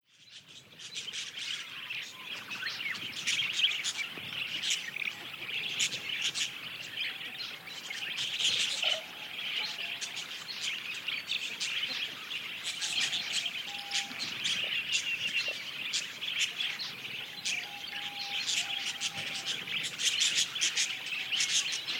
Звуки попугая
Волнистые попугаи чирикают стаей